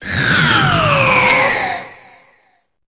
Cri de Lugulabre dans Pokémon Épée et Bouclier.